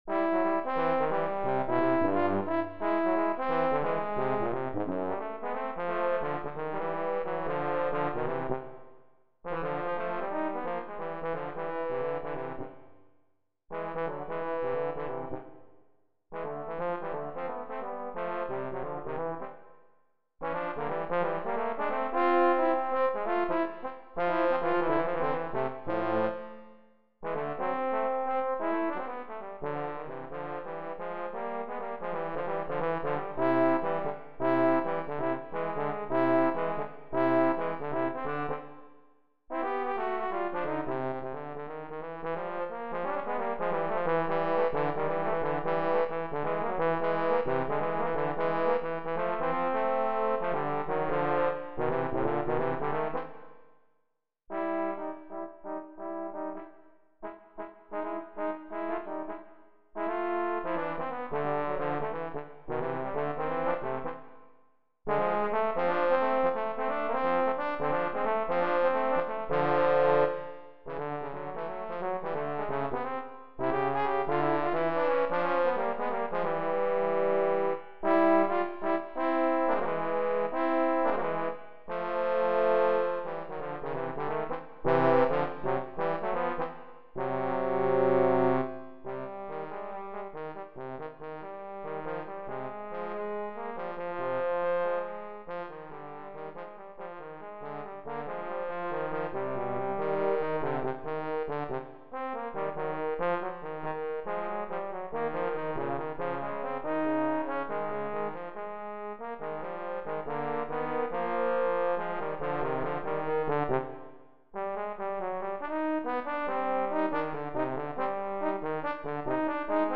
Gattung: für 2 Posaunen
Besetzung: Instrumentalnoten für Posaune